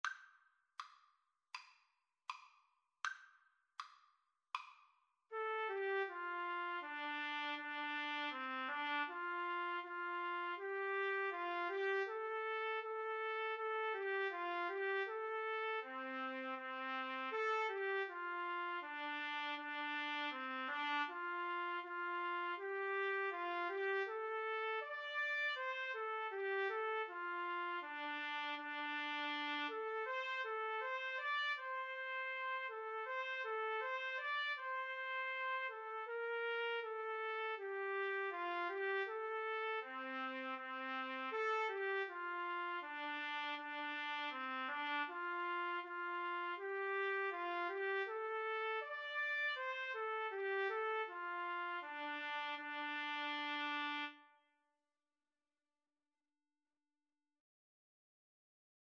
Andante Espressivo = c. 80
4/4 (View more 4/4 Music)